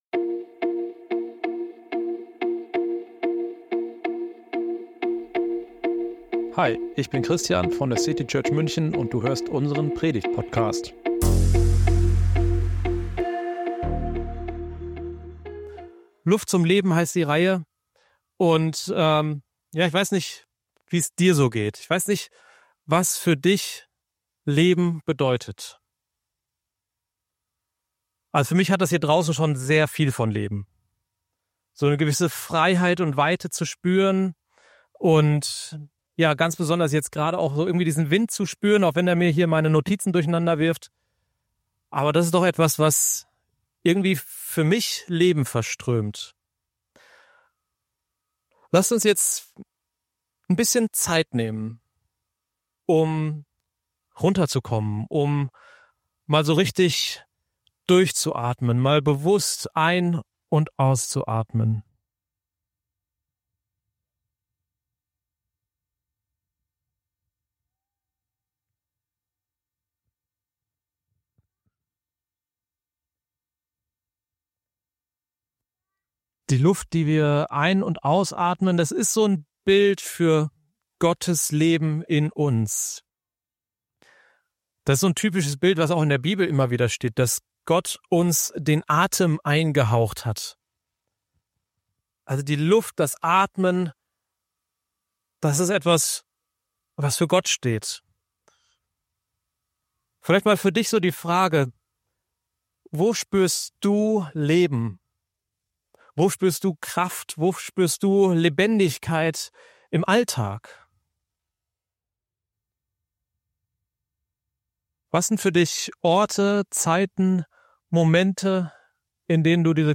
Weg vom Müssen und Sollen. In dieser Predigt geht es darum, Gott als Kraftquelle zu erleben.